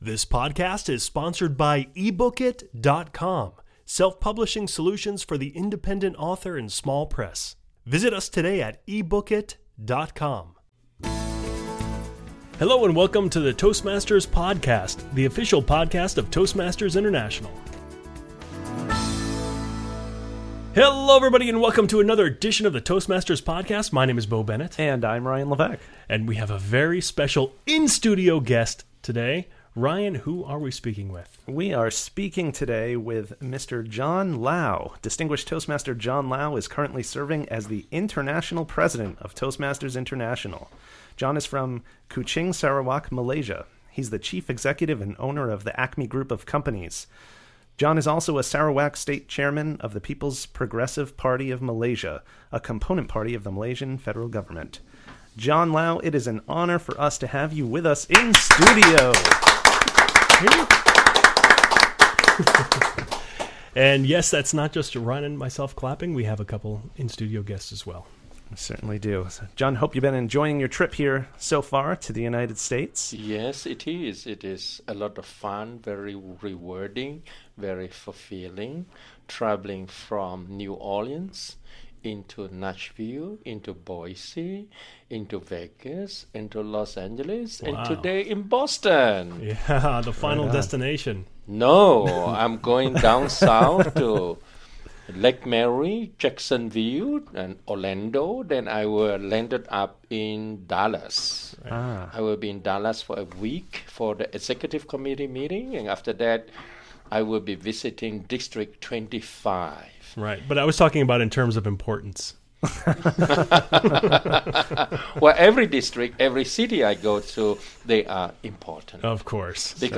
In this special in-studio interview